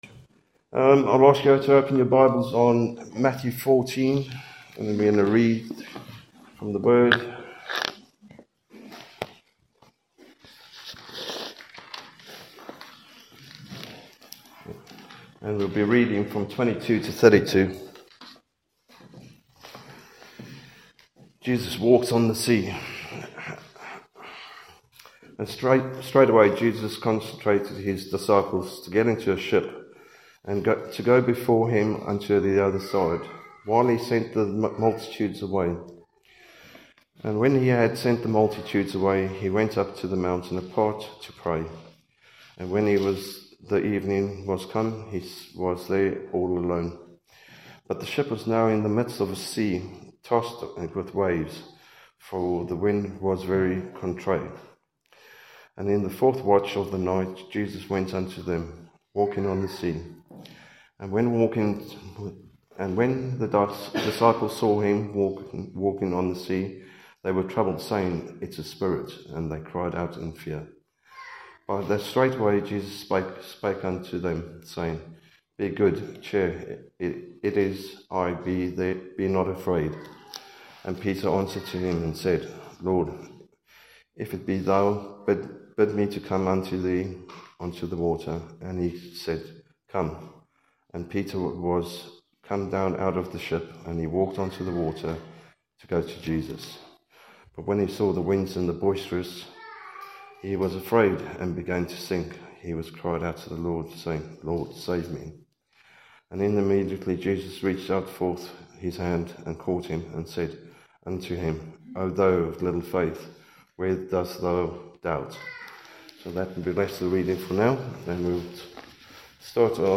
Come to The Lord – Testimony (21 mins)